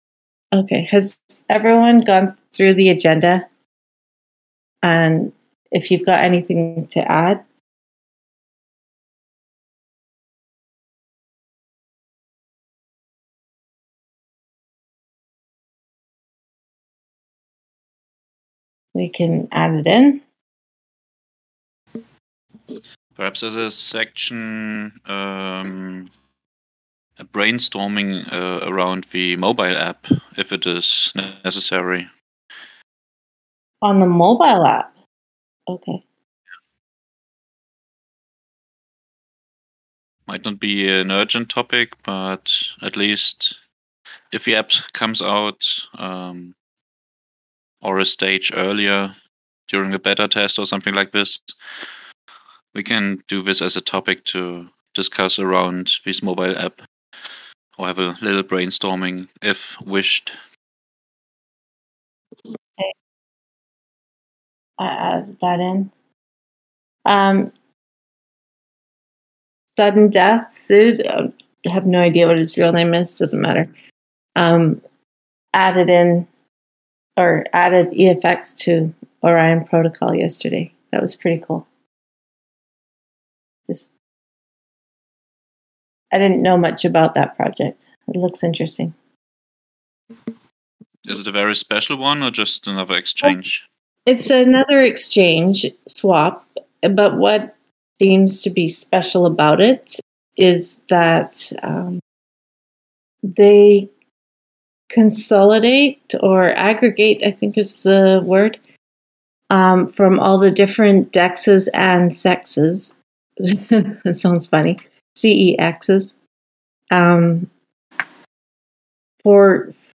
DAO Call - August 18, 2021